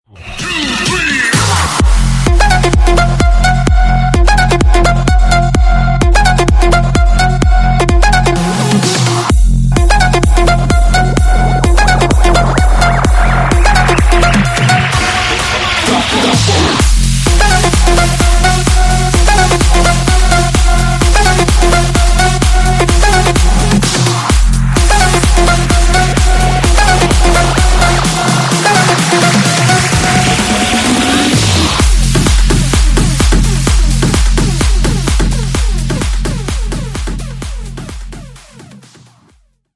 • Качество: 192, Stereo
dance
без слов
club
electro house